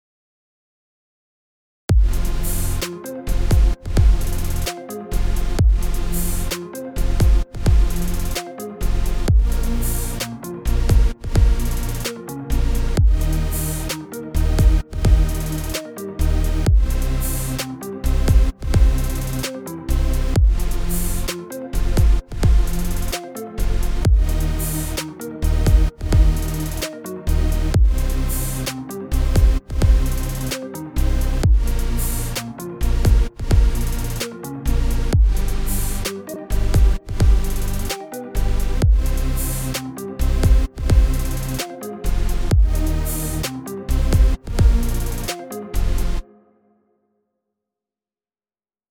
ראפ שבניתי RAP cw.WAV
הליד מאד יפה!!! התוף שמגיע בסוף לא מתאים לטרק, זה נשמע כמו סאונדים שמגיעים עם האורגן בלי שום הכפלות, גם הבס או שאין לו סיידציין או שהקיק הוא קיק עם קצת בס.